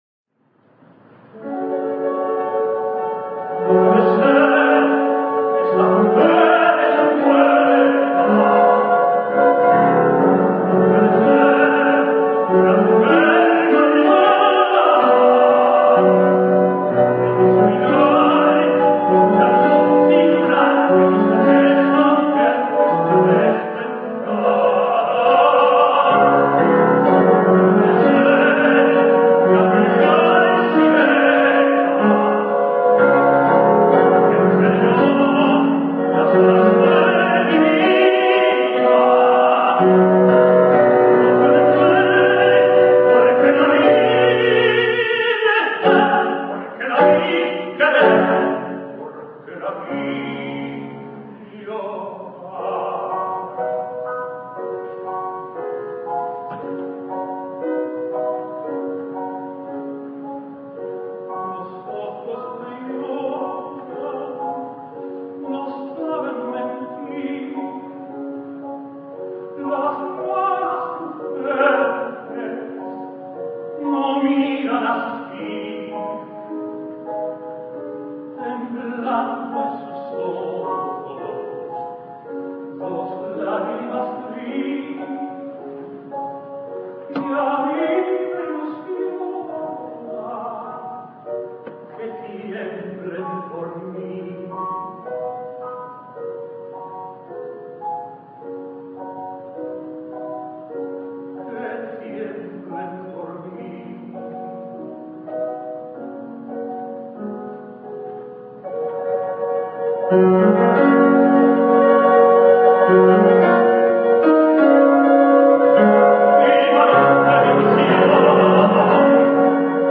Raúl Giménez sings La tabernera del puerto:
He was almost exclusively a specialist for belcanto, including many ultra-rare operas, and so quite confusingly, he and his (totally unrelated) contemporary Eduard Giménez sang basically the same repertory at the same time.